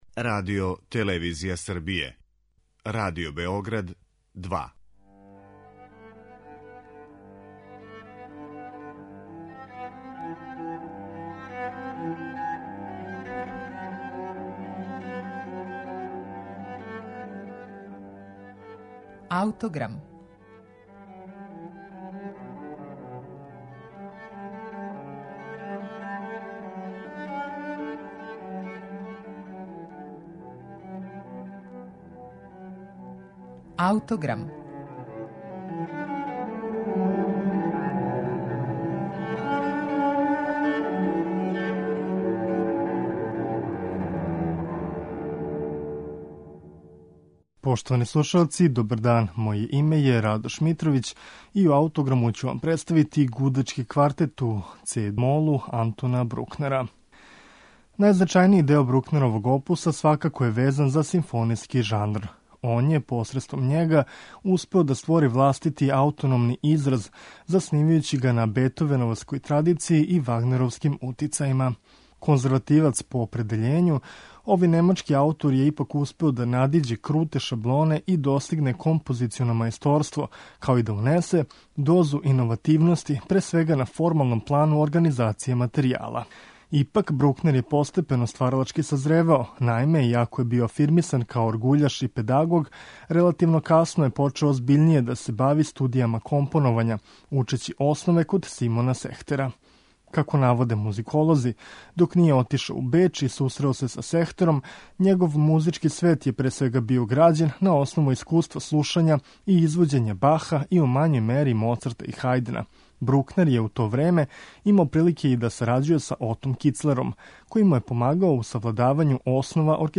Ipak, on je postepeno stvaralački sazrevao, a jedno od dela koje svedoči o tim počecima, svakako jeste Gudački kvartet. Slušaćemo ga u interpretaciji ansambla Larkibudeli.